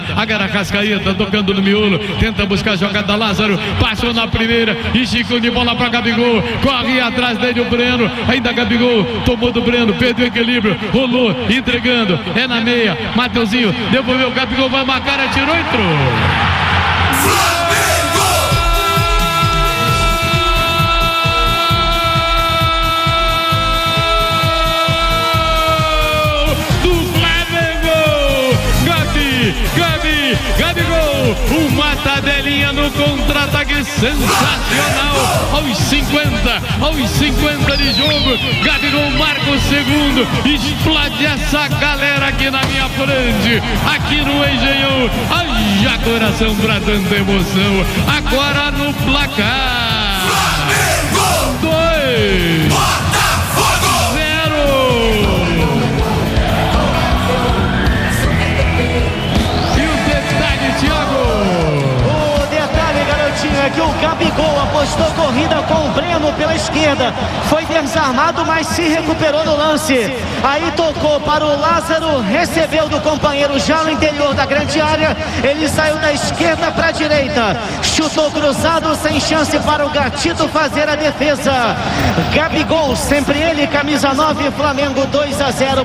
Ouça os gols da vitória do Flamengo sobre o Botafogo pelo Carioca com a narração do Garotinho